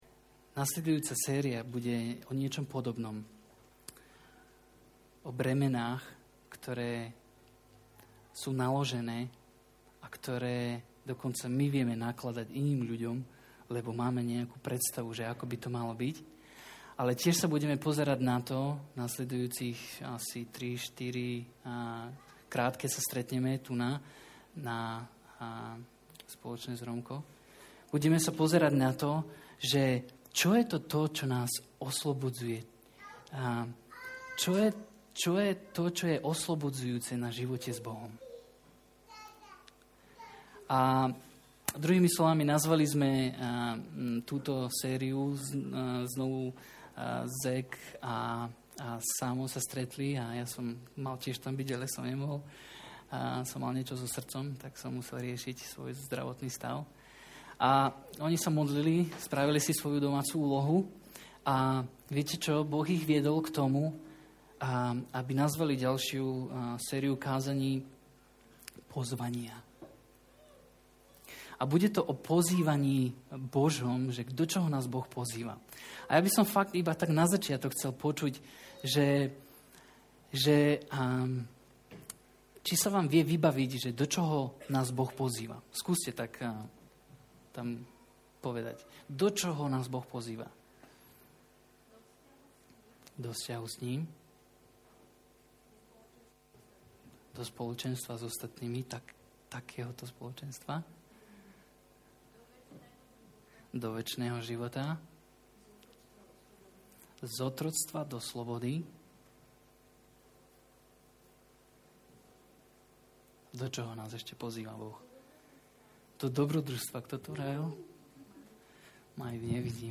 Nahrávka kázne Kresťanského centra Nový začiatok z 5. novembra 2006